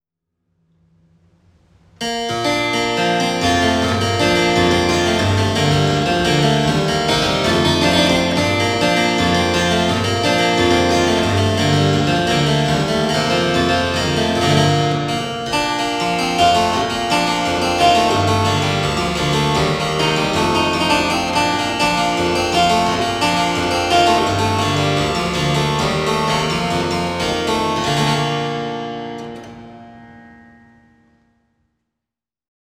Robert Goble English Double
Robert Goble English double 1973 8’8’4’ double buff stop.
The instrument is at 440 HZ pitch.